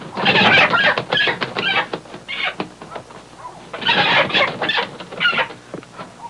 Hen Fight Sound Effect
Download a high-quality hen fight sound effect.
hen-fight.mp3